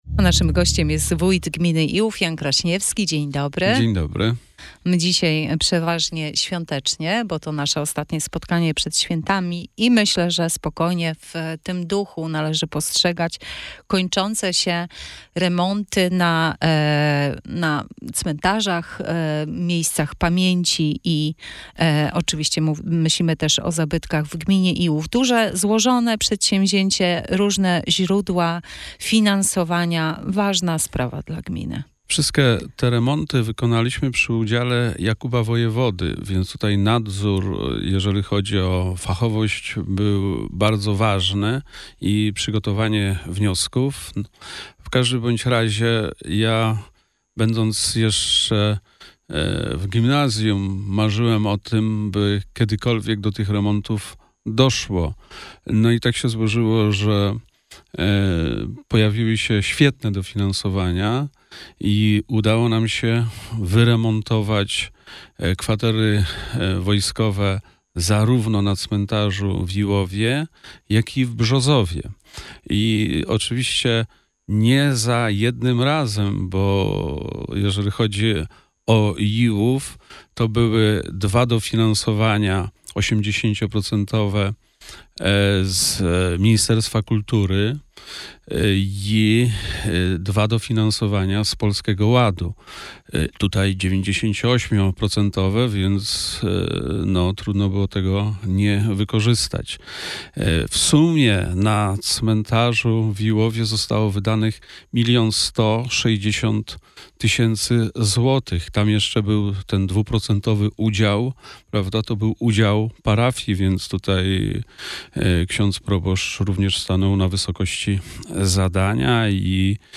Wywiad z Wójtem Janem Kraśniewskim w Radio Sochaczew - Najnowsze - Gmina Iłów